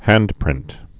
(hăndprĭnt)